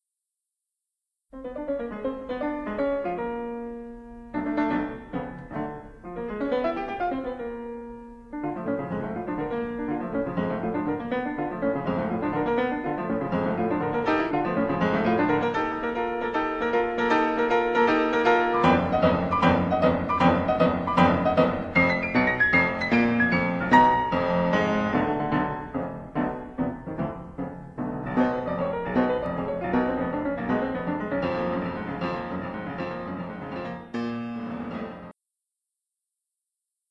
En aquest cas parlarem de Sergei Prokofiev, un dels grans pianistes i compositors russos del segle XX, els quals l’amor per la música li venia de la seva mare.
Prokofiev-plays-sonata-7-1932tros.mp3